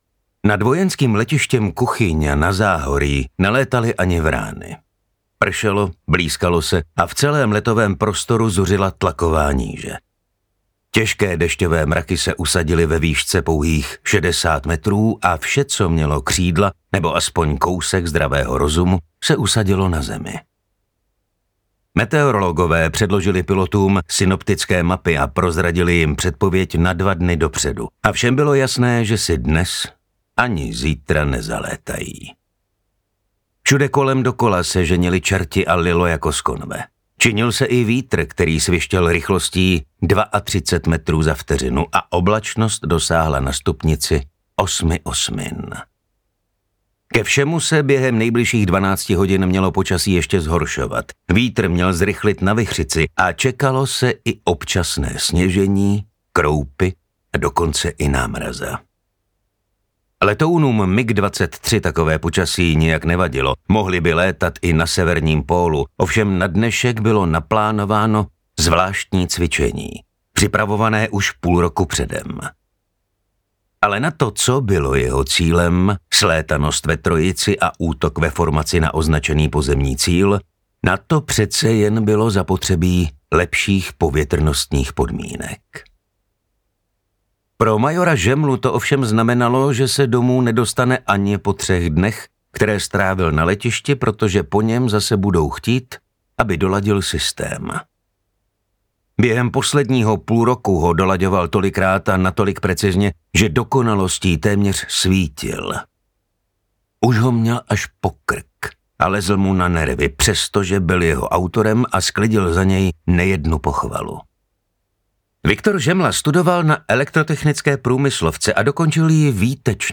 Uzel audiokniha
Ukázka z knihy
• InterpretMartin Stránský